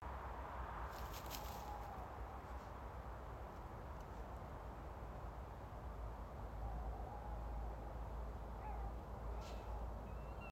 Tawny Owl, Strix aluco
StatusVoice, calls heard